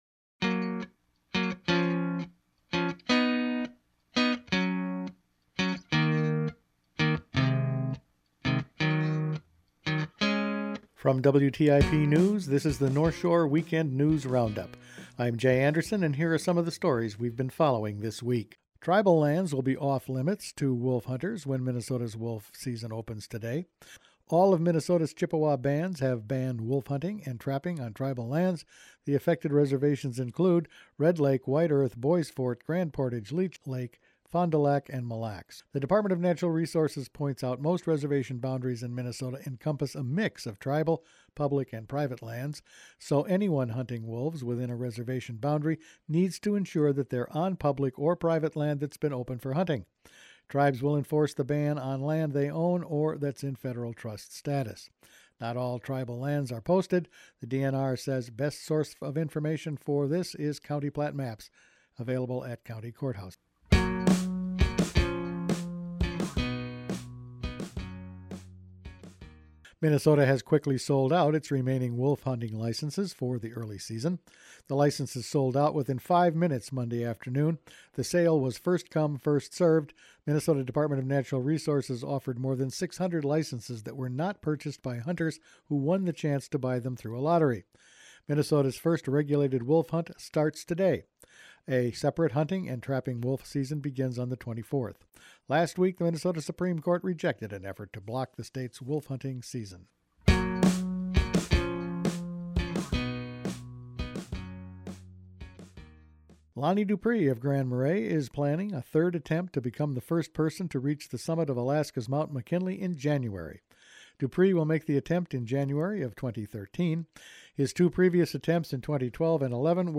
Each weekend WTIP news produces a round up of the news stories they’ve been following this week. No wolf hunting on tribal lands, big beach clean-up, pollution fines , politics and pipelines…all in this week’s news.